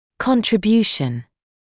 [ˌkɔntrɪ’bjuːʃn][ˌконтри’бйуːшн]вклад; ценные достижения